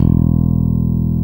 Index of /90_sSampleCDs/Roland L-CDX-01/BS _E.Bass 1/BS _5str v_s